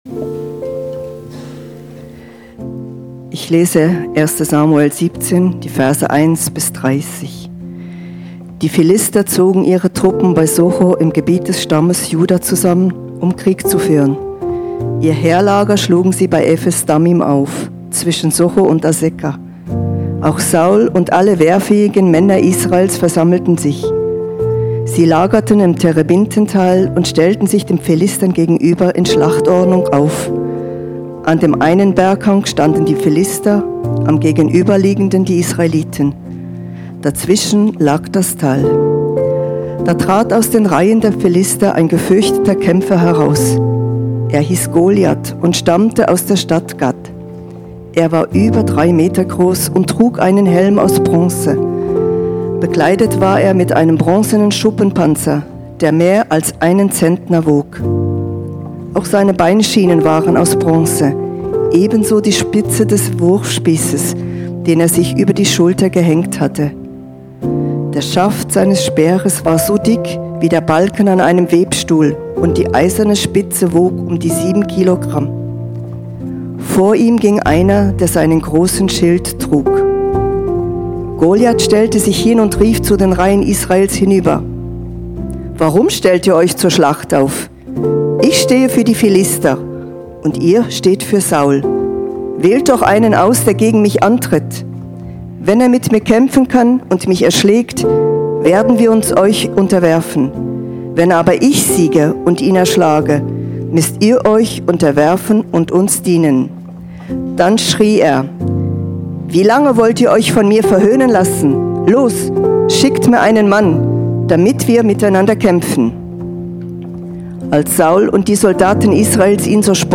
Online Predigt